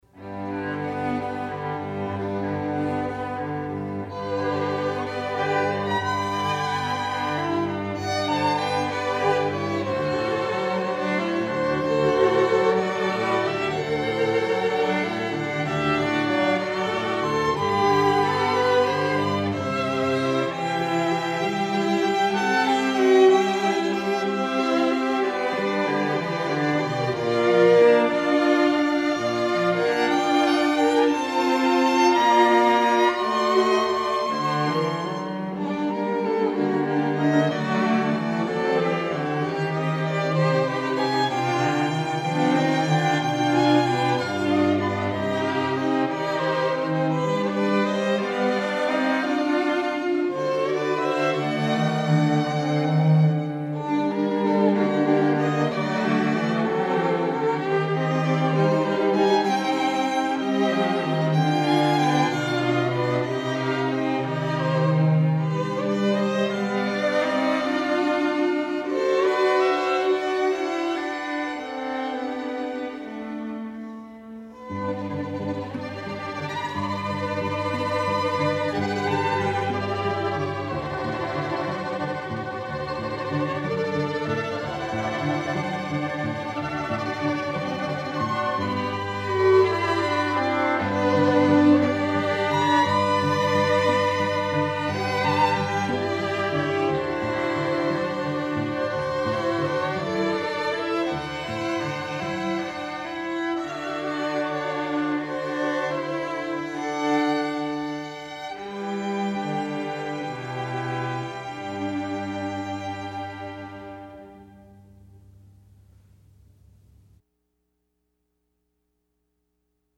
Strings
Each player of this string quartet brings a diversity of experience, from studies with world renown teachers, to performances with great orchestras.